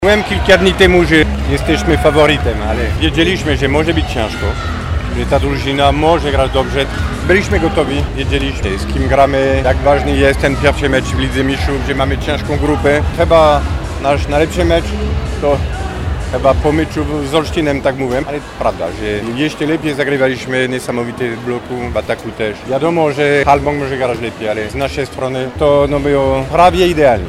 – mówił trener gospodarzy, Stephane Antiga.